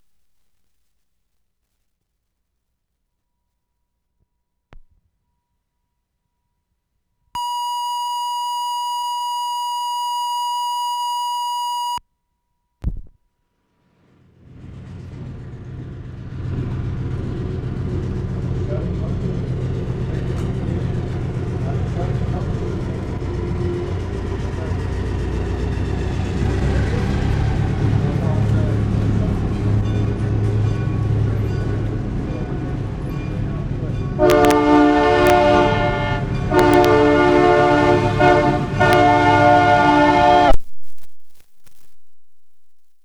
TRAIL, B.C. Nov. 15, 1973
COMINCO, train whistle 0'30"
2. The whistle is loud and clear with an odd "dry" echo -- almost like a different whistle coming back. Bell ringing before train whistle starts.